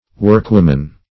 Search Result for " workwoman" : The Collaborative International Dictionary of English v.0.48: Workwoman \Work"wom`an\, n.; pl.
workwoman.mp3